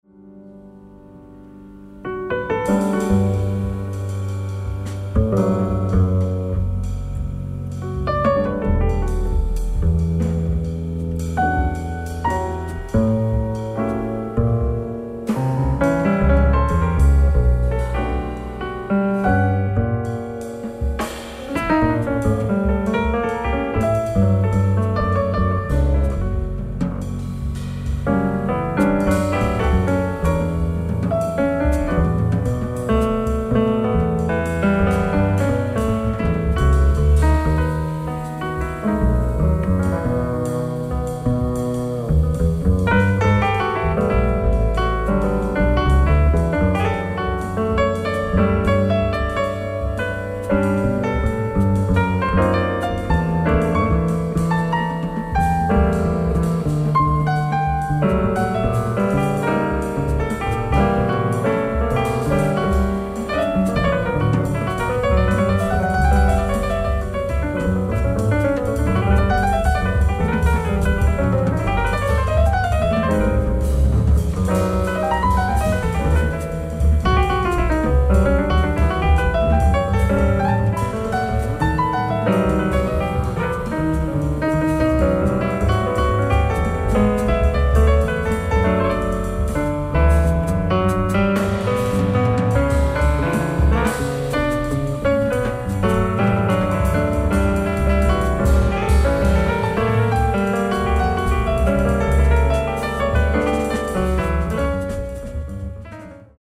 ライブ・アット・アゴラ劇場、モンペリエ、フランス 07/08/2025
オフィシャル級の美音ライブ！！
※試聴用に実際より音質を落としています。